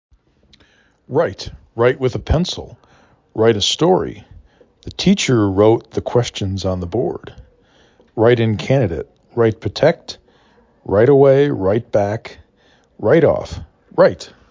write 3 /r/ /I/ /t/ Frequency: 743
r I t